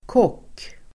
Ladda ner uttalet
Uttal: [kåk:]
kock.mp3